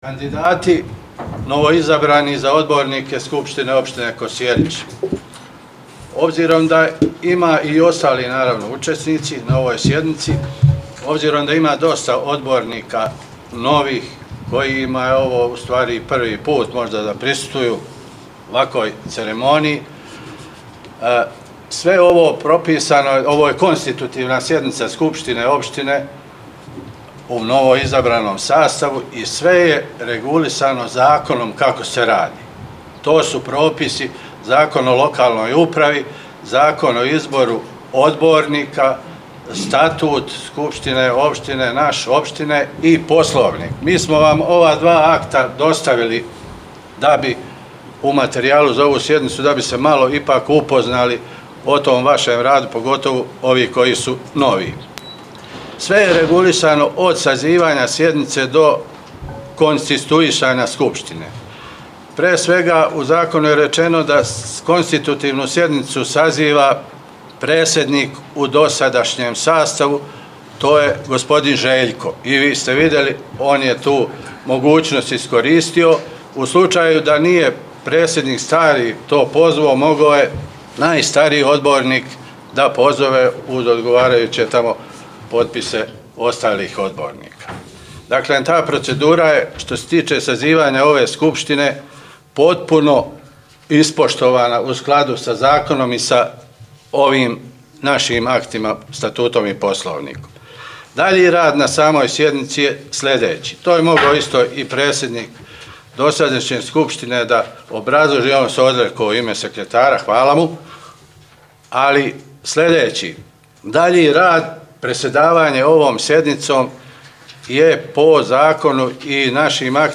1. KONSTITUTIVNA SEDNICA SKUPŠTINE OPŠTINE KOSJERIĆ
Održana u petak, 21. juna 2013. godine u sali 11 na prvom spratu Narodne biblioteke u Kosjeriću sa početkom u 10,00 časova.
Zvučni zapis sa sednice: